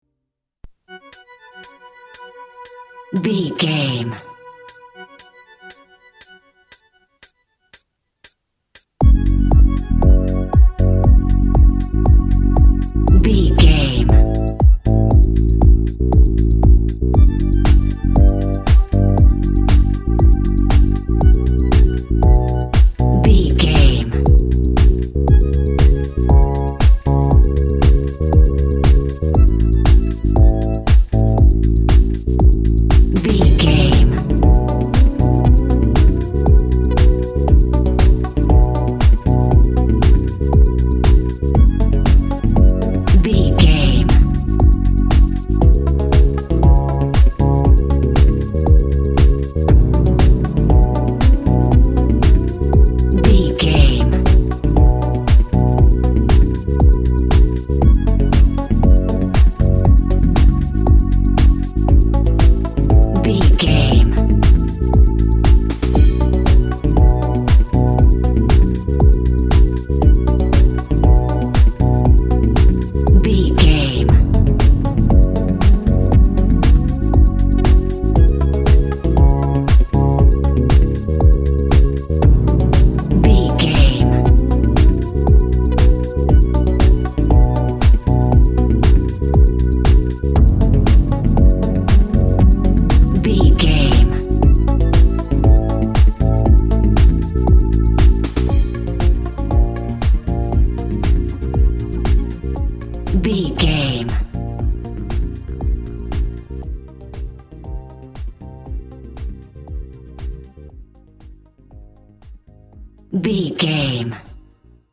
Aeolian/Minor
futuristic
hypnotic
industrial
dreamy
smooth
drums
synthesiser
electric guitar
techno
trance
glitch
synth lead
synth bass
Electronic drums
Synth pads